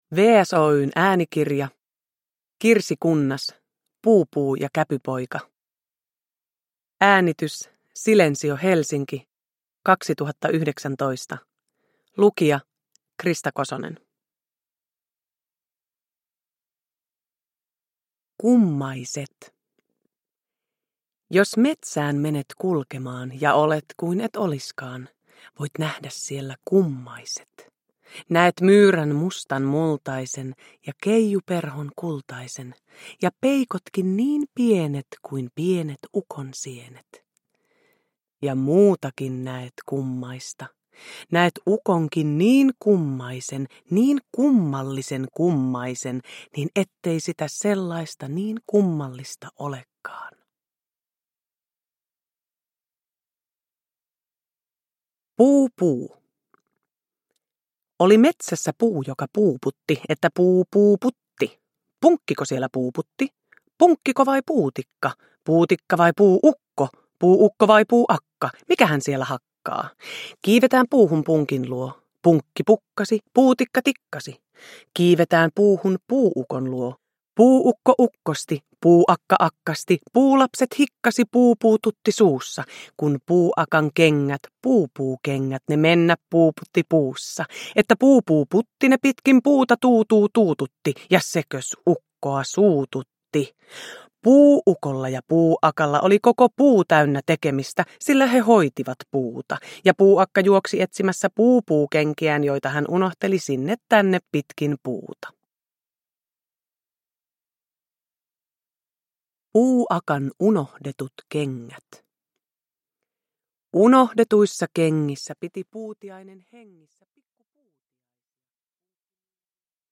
Kirsi Kunnaksen rakastettuja lastenrunoja ilmestyy ensi kertaa äänikirjoina Krista Kososen lukemina.
Uppläsare: Krista Kosonen